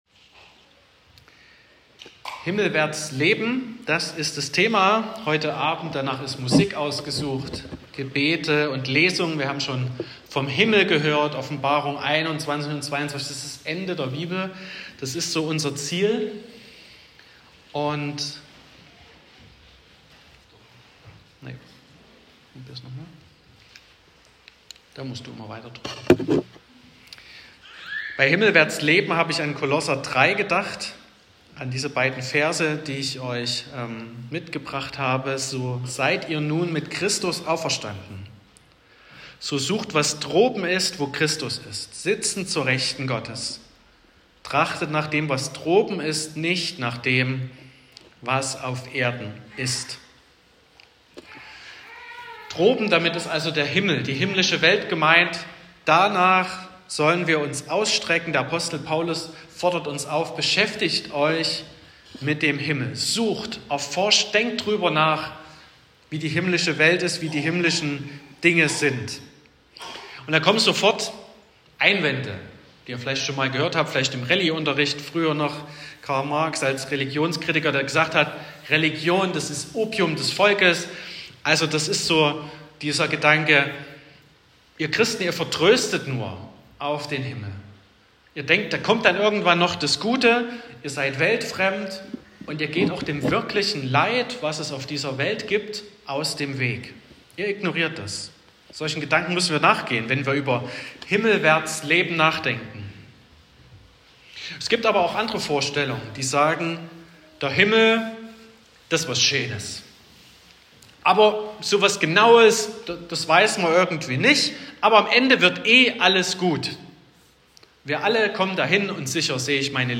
Predigt (Audio): 2025-11-02_Himmelwaerts_leben.m4a (14,2 MB)